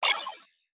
Game Notification Particle Ping.wav